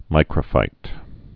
(mīkrə-fīt)